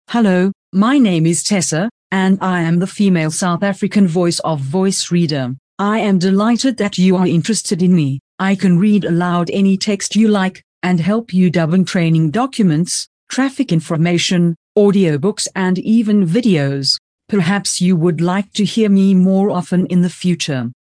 Voice Reader Studio 22 English (South African)
Professionelle Sprachausgabe zum Vorlesen und Vertonen beliebiger Texte
Die Stimmen klingen so natürlich, dass sie von menschlichen Sprechern kaum noch zu unterscheiden sind.
• Die Text-to-Speech Software bietet Ihnen alles, was Sie für die professionelle Vertonung benötigen